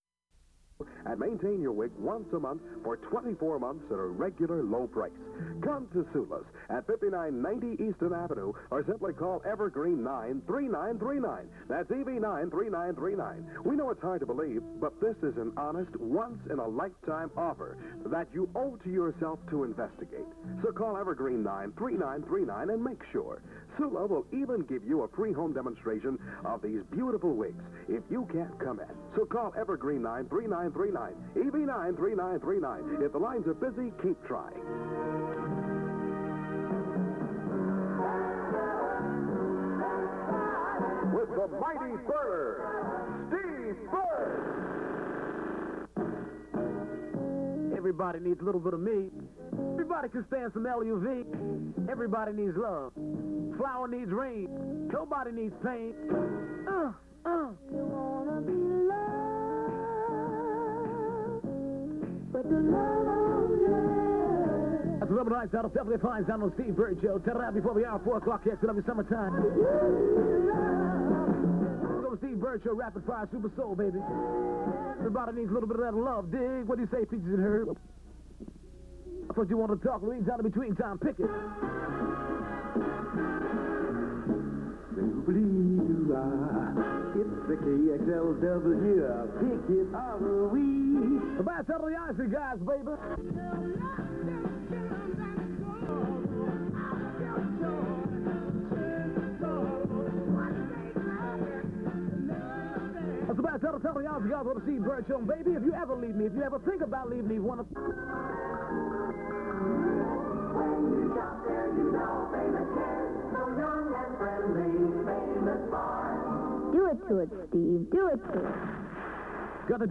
Transcription aircheck